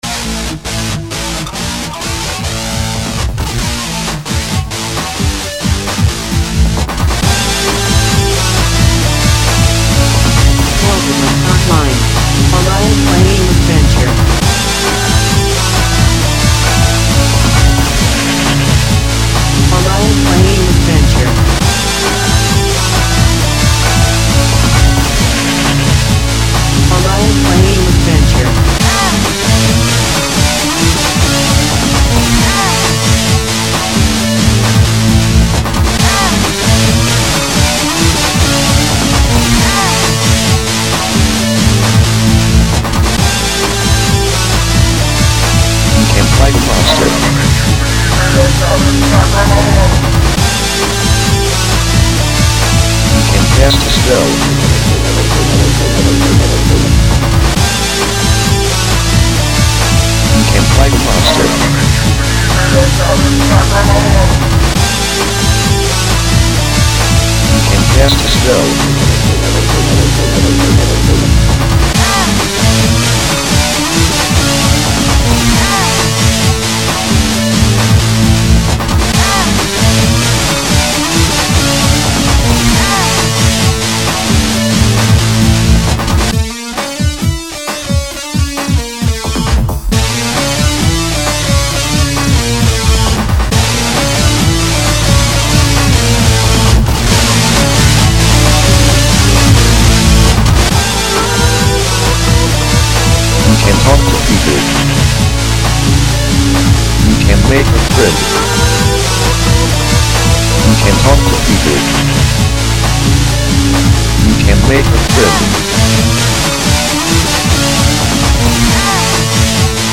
The mix came out pretty good.  I used robot voices, so I did not have to impose my Gomer Pyle-esque voice on everyone.